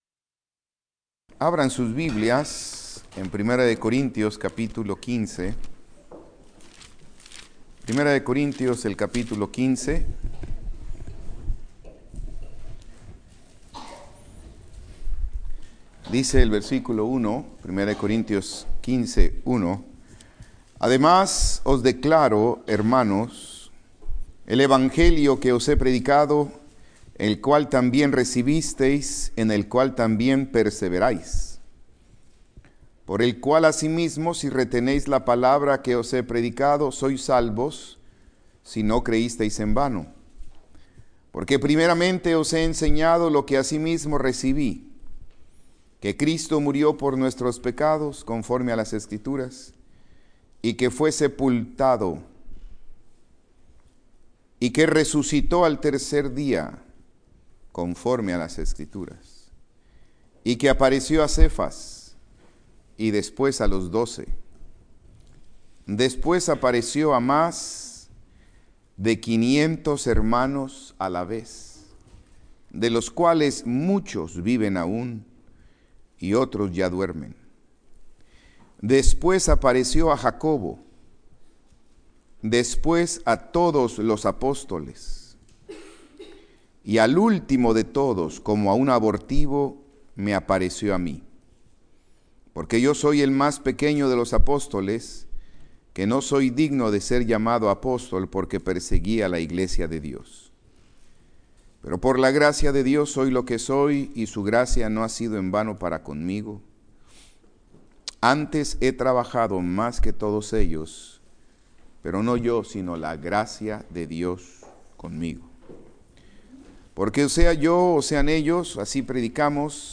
Conferencia Bíblica VII.15 - Las evidencias y resultados de la resurrección de Cristo - Iglesia Cristiana Biblica Monte Moriah